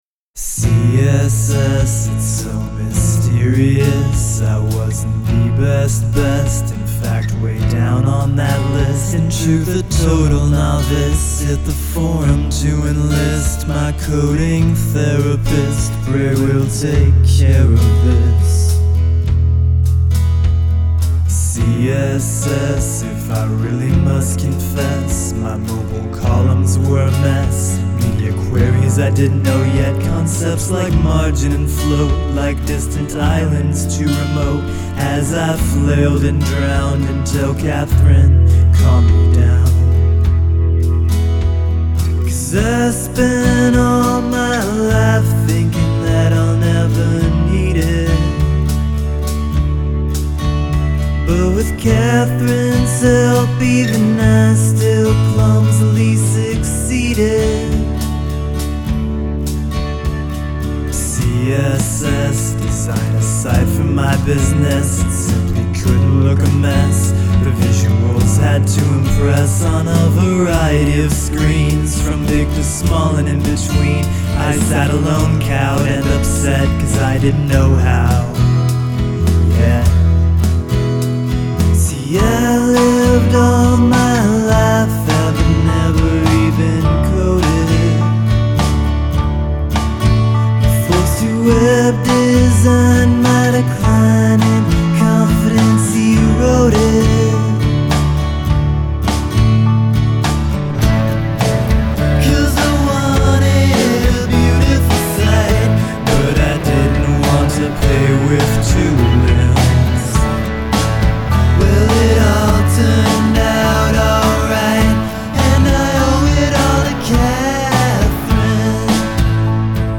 guitar
by Custom Song Workshop